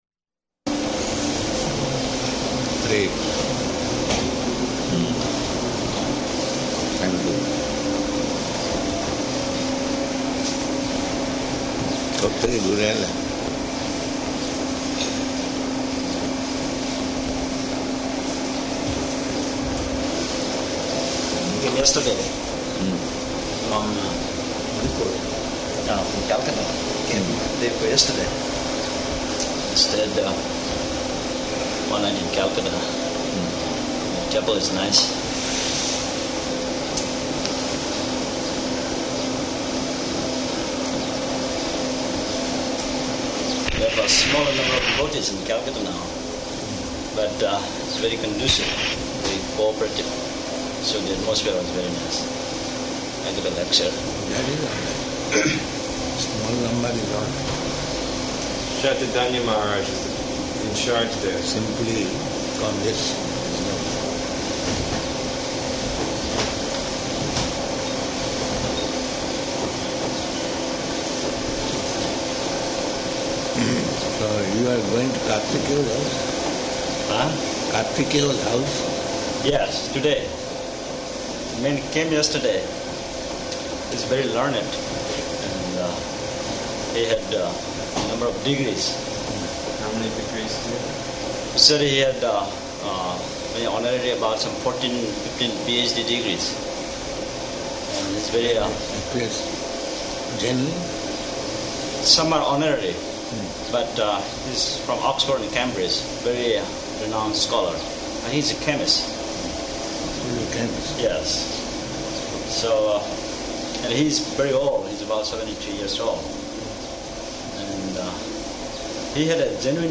-- Type: Conversation Dated: April 18th 1977 Location: Bombay Audio file